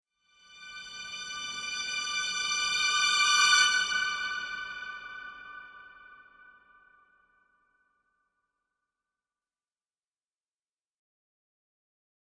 Strings|Violins
Violins, Crescendo Reminder, Type 1, Happy